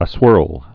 (ə-swûrl)